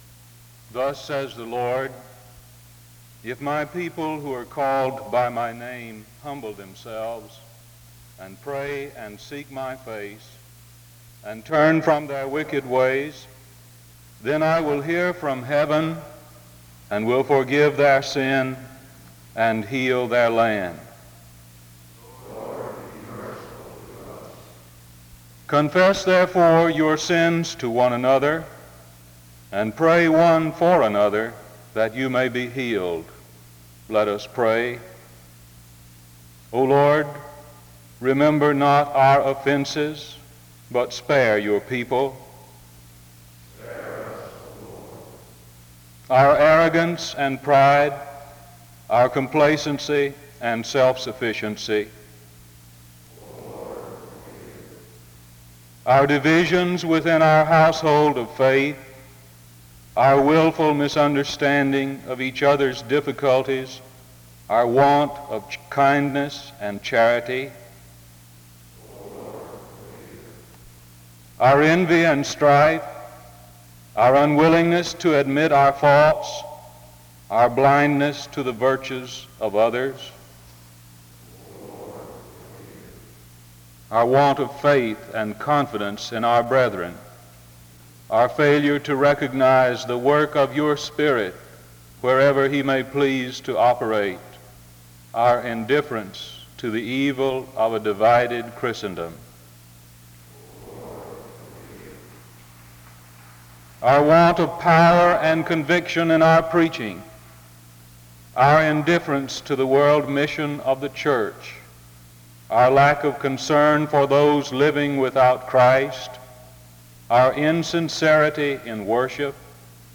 The service opens with a responsive reading from 0:00-2:33.